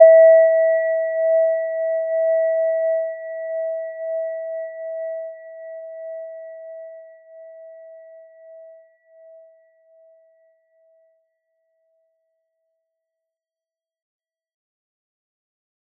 Gentle-Metallic-1-E5-p.wav